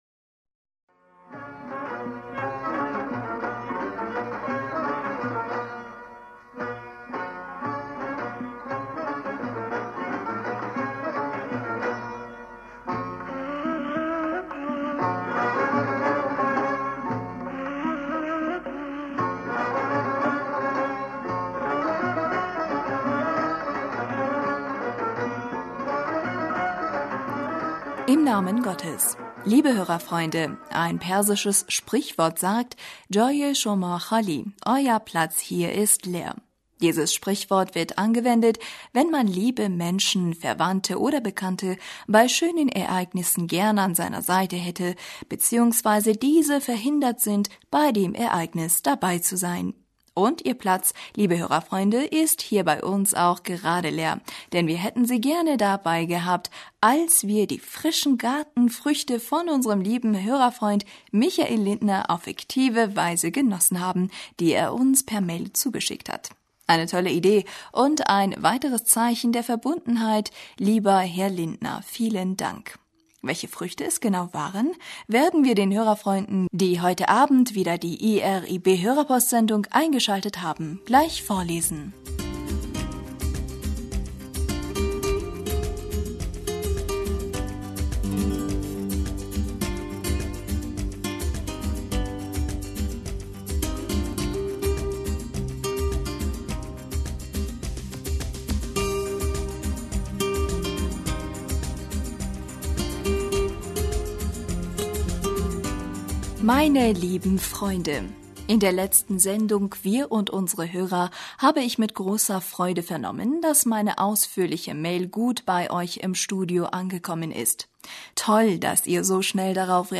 Hörerpostsendung am 28. August 2016 Bismillaher rahmaner rahim - Liebe Hörerfreunde, ein persisches Sprichwort sagt جای شما خالی